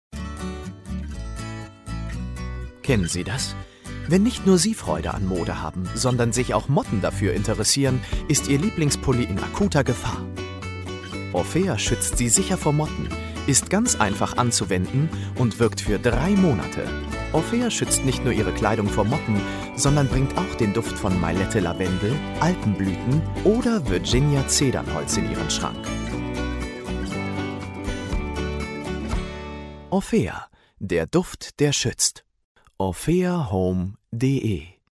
Kinotrailer
Podcast Intro